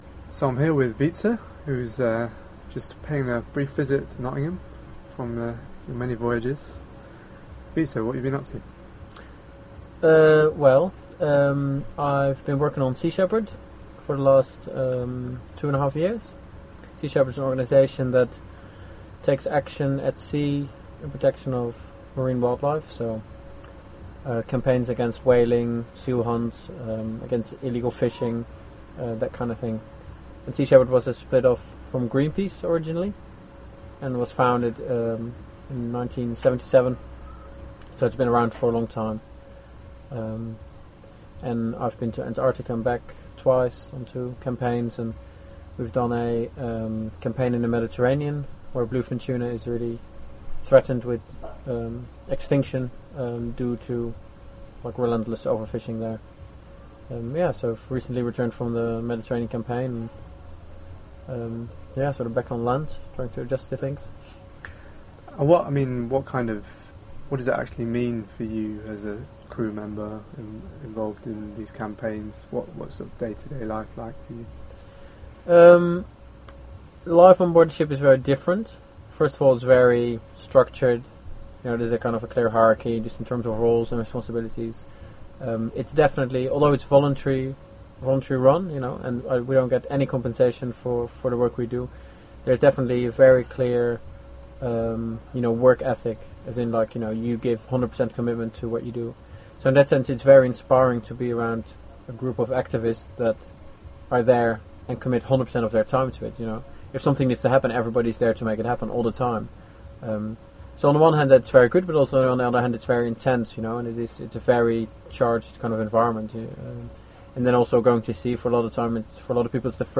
Nottingham Indymedia | Articles | Show | Interview